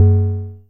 Vermona DRM 1 " Vermona Hi Hat 10
描述：来自Vermona DRM 1模拟鼓声合成器的Hi Hat通道
标签： 模拟 Vermona DRM 1 合成器 样品
声道立体声